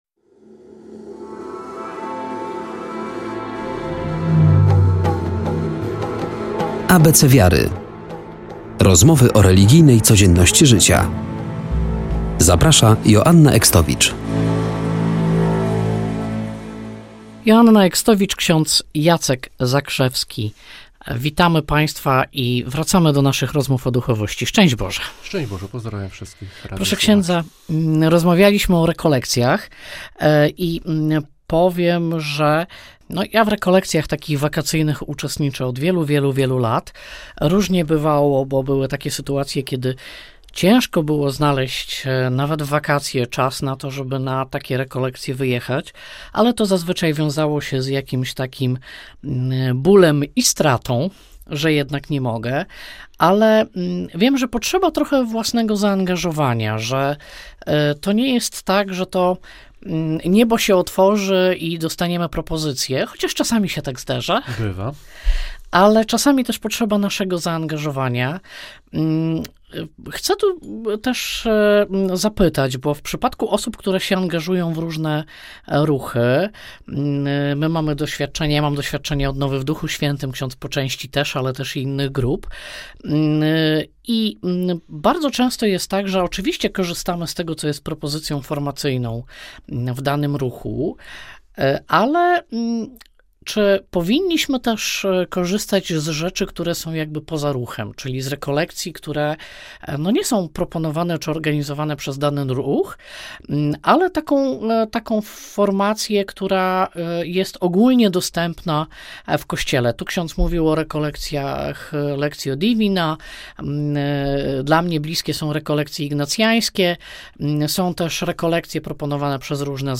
Poprzednie audycje z cyklu – duchowość